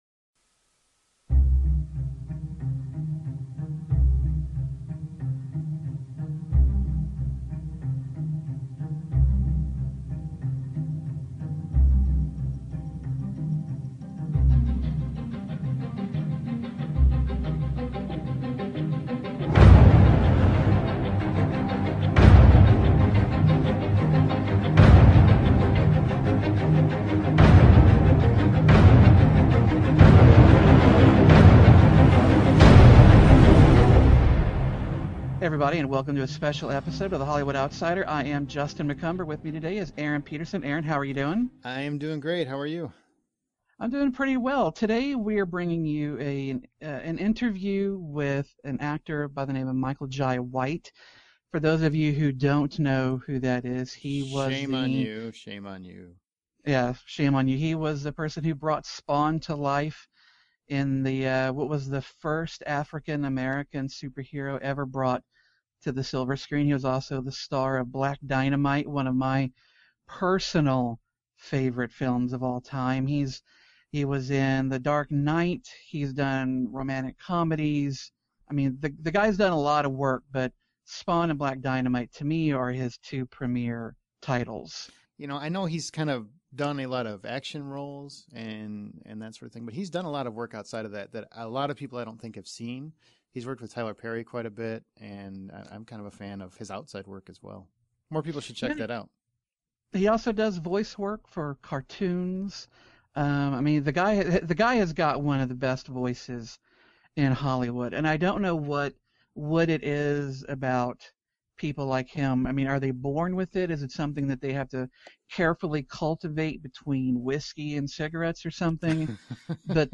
An Interview with Michael Jai White